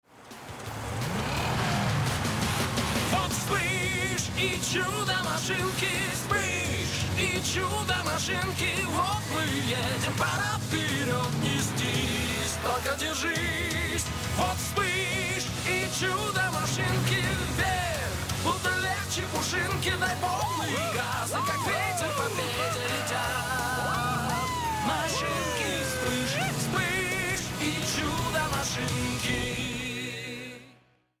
• Качество: Хорошее
• Жанр: Детские песни
🎶 Детские песни / Песни из мультфильмов